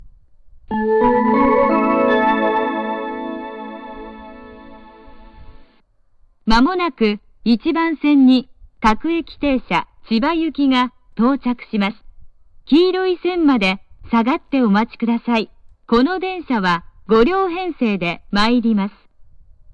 発車メロディー
余韻まで鳴れば上等？
･音質：良